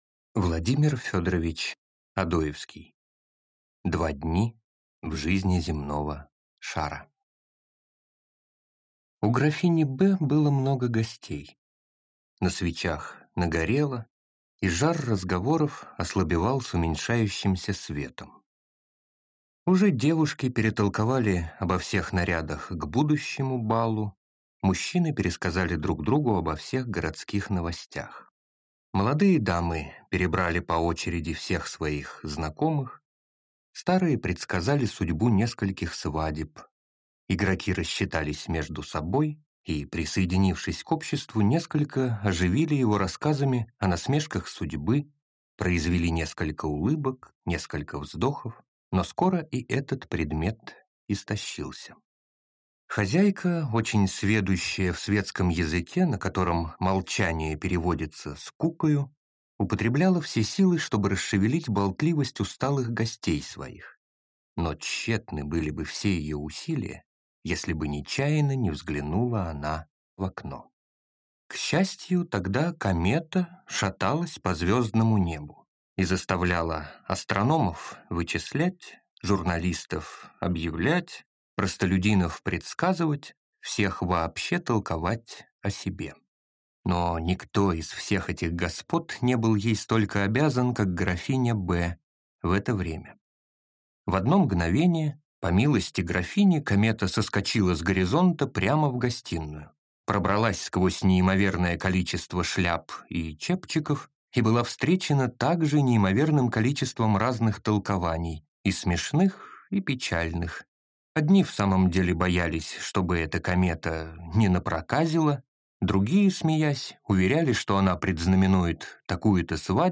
Аудиокнига Два дни в жизни земного шара | Библиотека аудиокниг
Прослушать и бесплатно скачать фрагмент аудиокниги